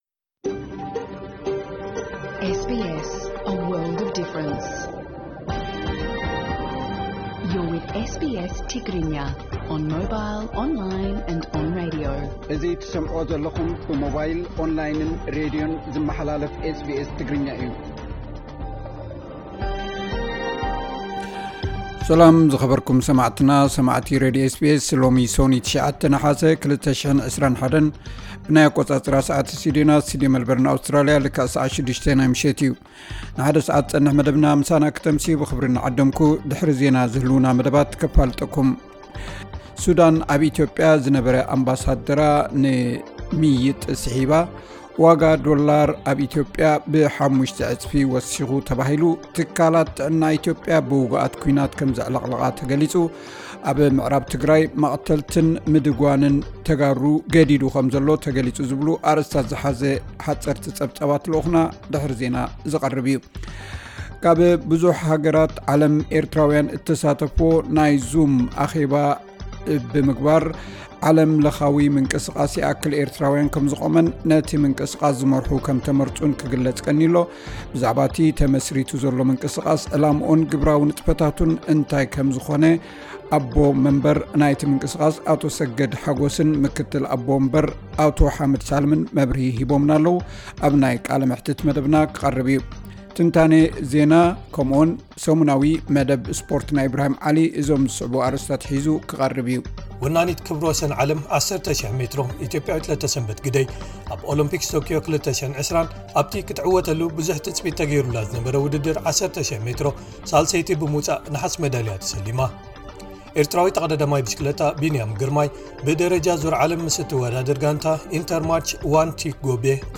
ዕለታዊ ዜና 09 ነሓሰ 2021 SBS ትግርኛ